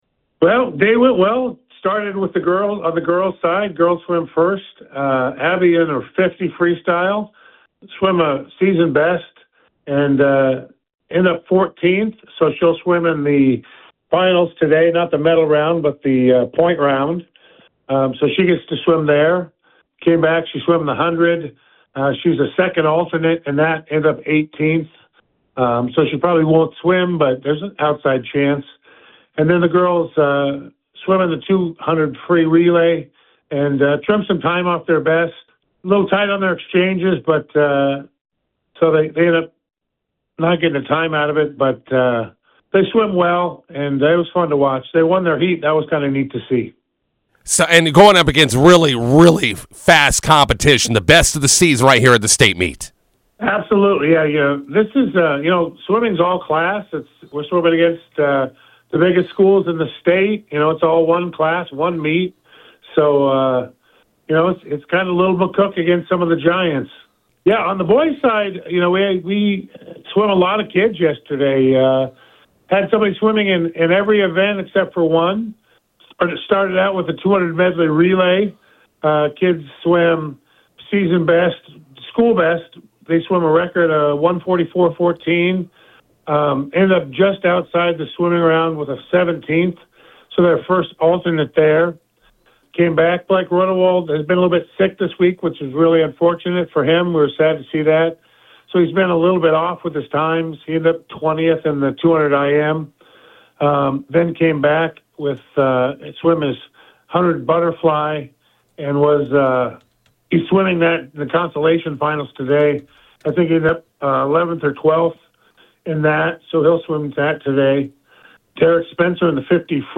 INTERVIEW: Bison swimmers break a few MHS school records on day two of the state meet in Lincoln.